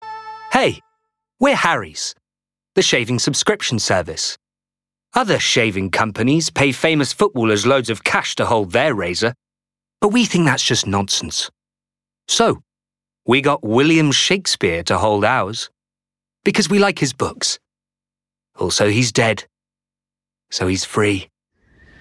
• Male
Showing: Commerical Clips
Upbeat, Light Hearted, Comedic